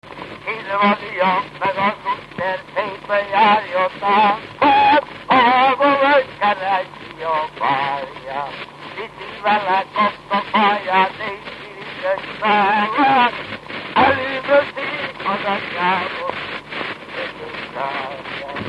Dunántúl - Somogy vm. - Szenna
ének
Gyűjtő: Lajtha László
Stílus: 7. Régies kisambitusú dallamok
Szótagszám: 14.14